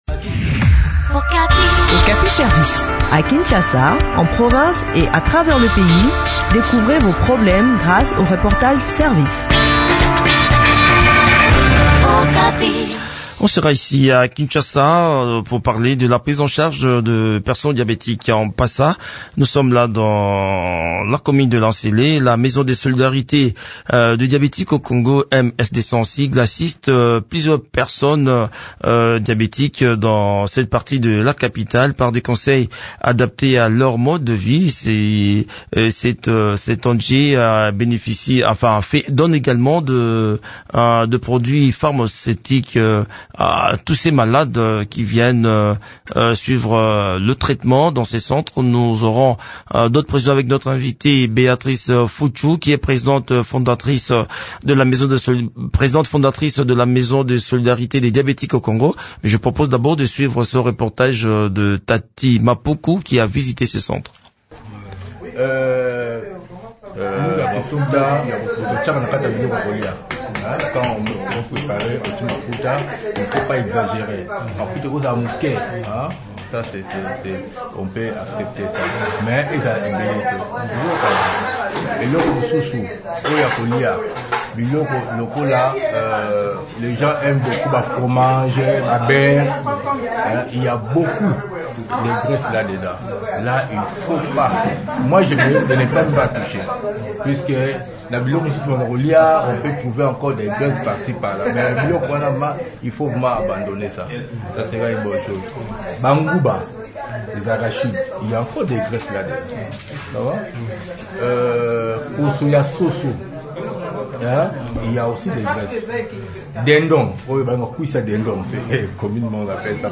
Le point sur cette assistance médicosociale dans cet entretien